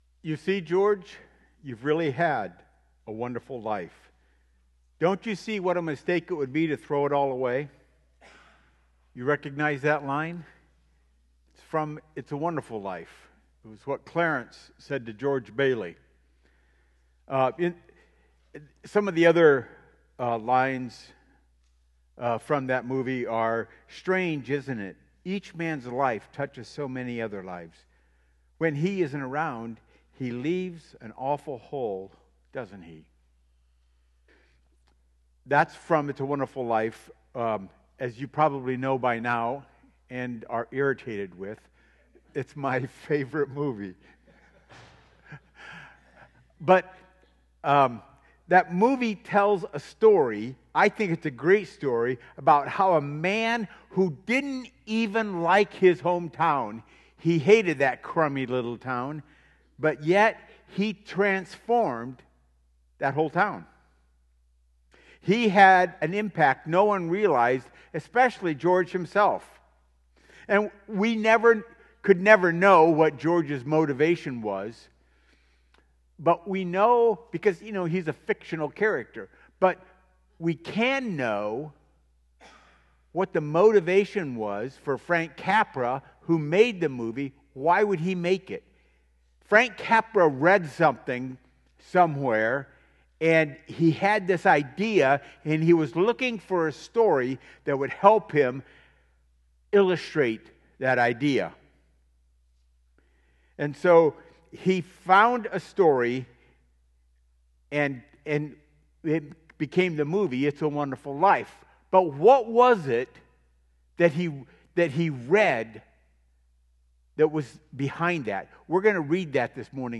Sermons | Warsaw Missionary Church